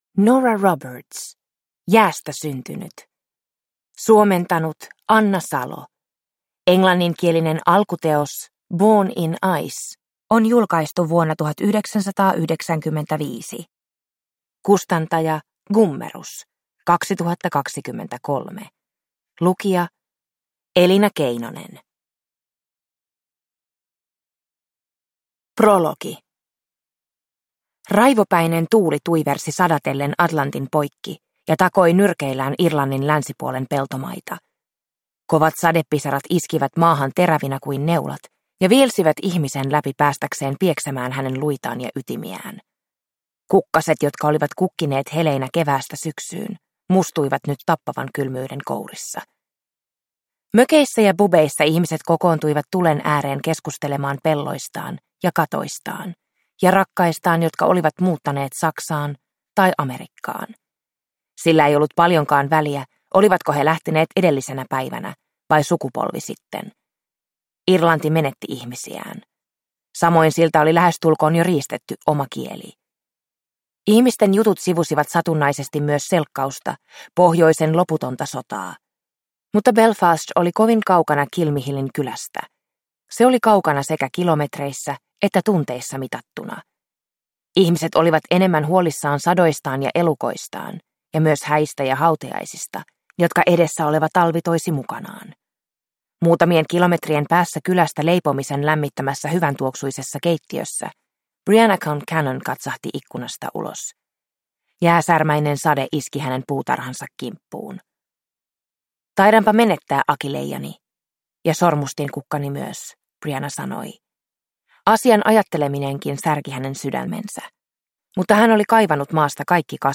Jäästä syntynyt – Ljudbok – Laddas ner